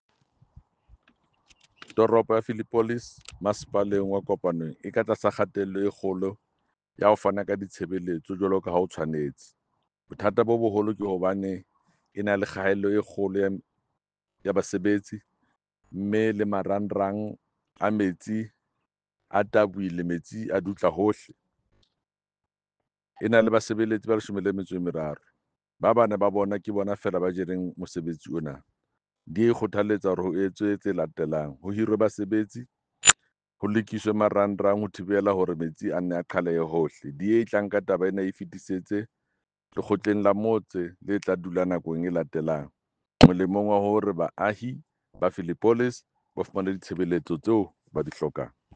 Sesotho by Jafta Mokoena MPL.
Sotho-voice-Jafta-3.mp3